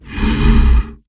MONSTER_Breath_02_Fast_mono.wav